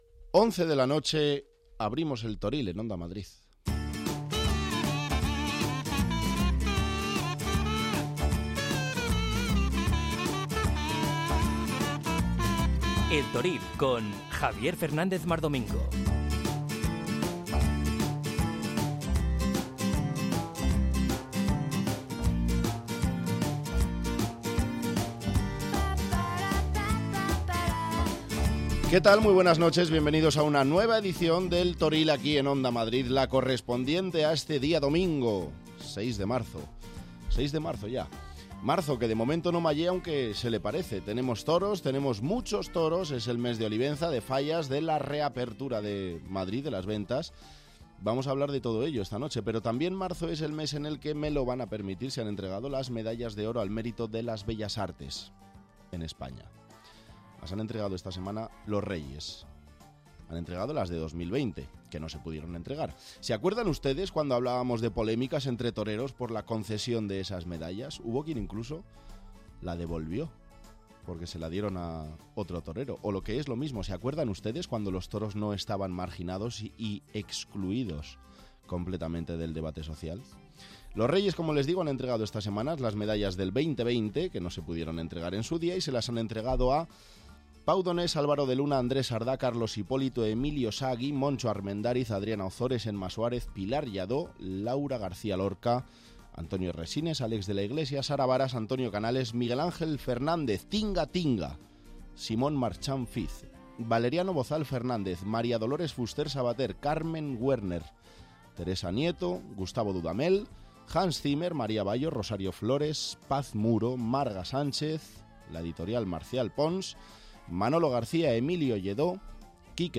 Habrá información pura y dura yentrevistas con los principales protagonistas de la semana.